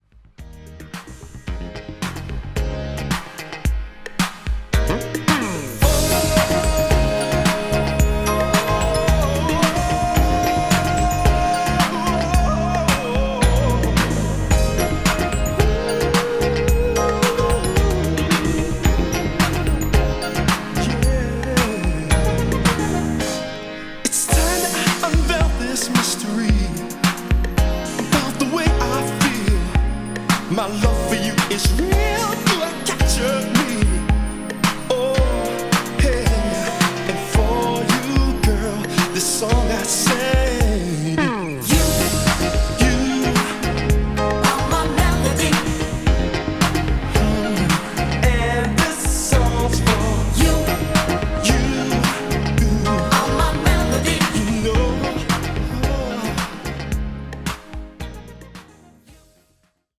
ホーム ｜ SOUL / FUNK / RARE GROOVE / DISCO > SOUL